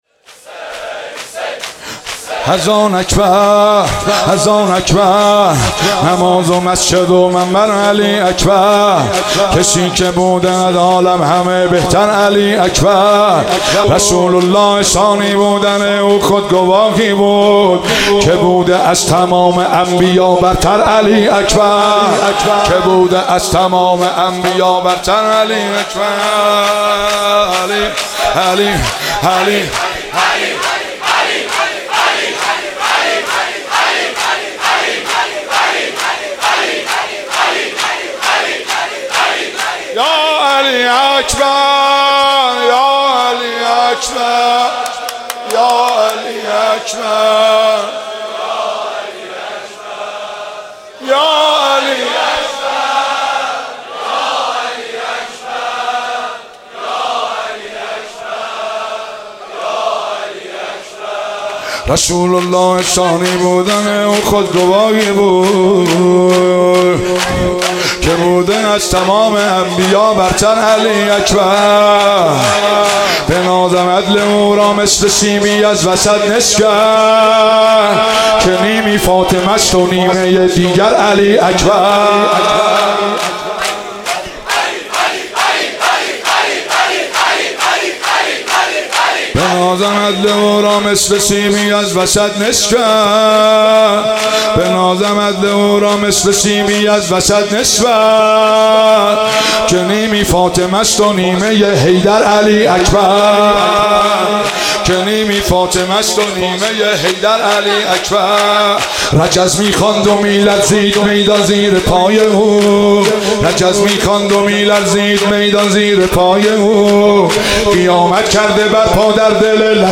شب هشتم محرم 96 - هیئت فاطمیون - زمزمه و شور - اذان اکبر